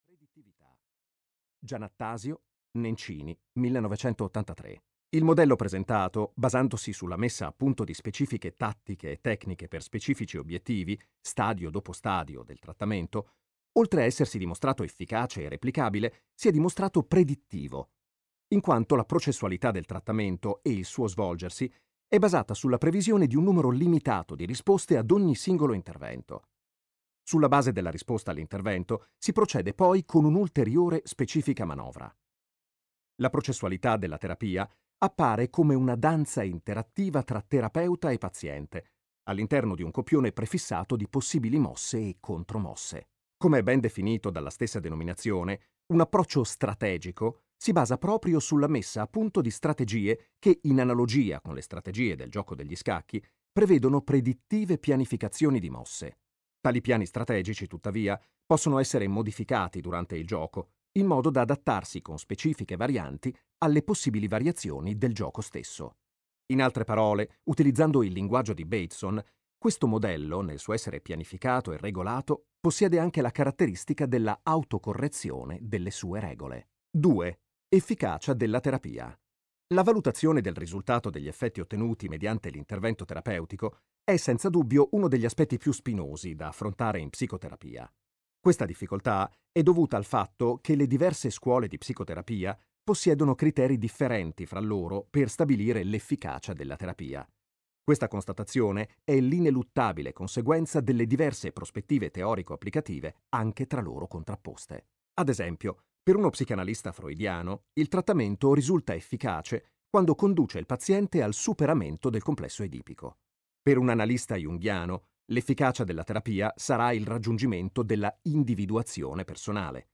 "Paura, panico, fobie" di Giorgio Nardone - Audiolibro digitale - AUDIOLIBRI LIQUIDI - Il Libraio